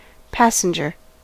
Ääntäminen
US : IPA : [ˈpæ.sən.dʒɝ]